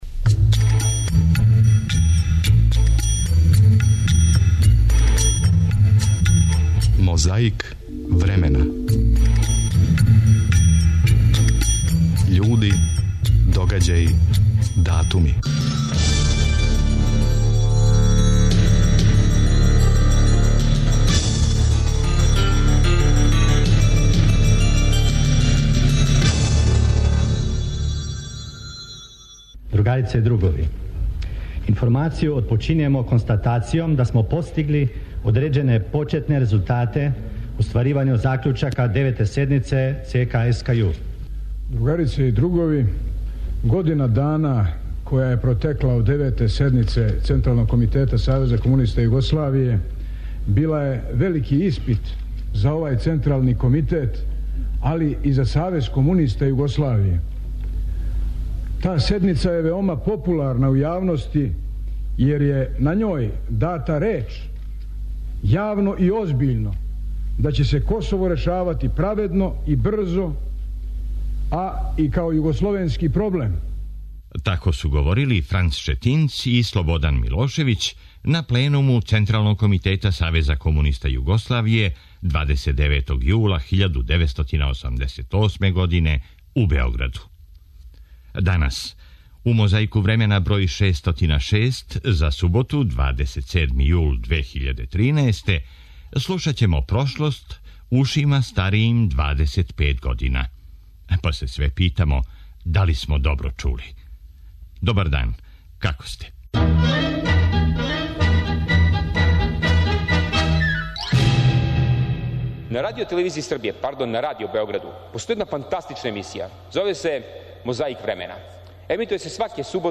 Први: 26. јула 1996. гостовао је у емисији "Гравитације" БК телевизије.
После параде, поздрава, застава и бацања шапки, врховни командант се обратио новопеченим потпоручницима.
Подсећа на прошлост (културну, историјску, политичку, спортску и сваку другу) уз помоћ материјала из Тонског архива, Документације и библиотеке Радио Београда.